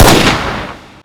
sol_reklam_link sag_reklam_link Warrock Oyun Dosyalar� Ana Sayfa > Sound > Weapons > AK Dosya Ad� Boyutu Son D�zenleme ..
WR_fire.wav